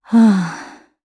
Isolet-Vox_Sigh_jp.wav